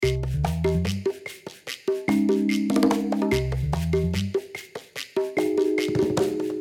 10秒BGM （103件）